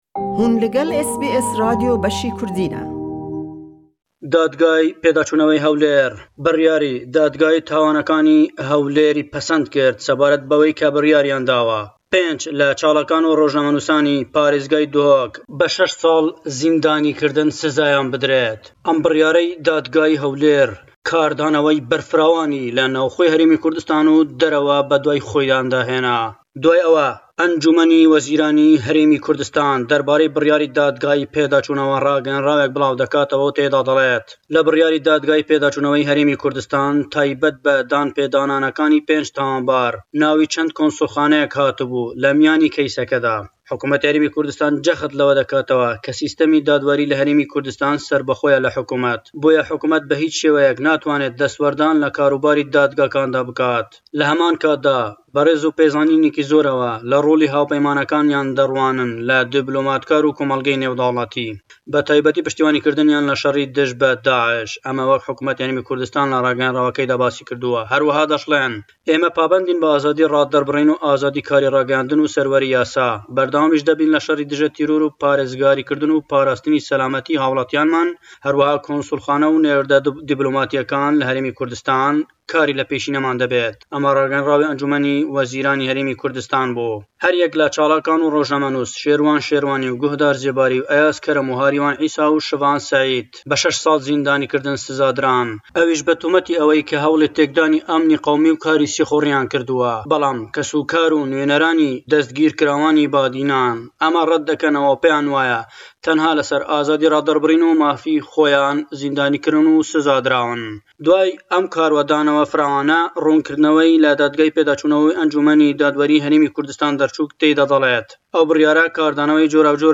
Le em raporte da le Hewlêre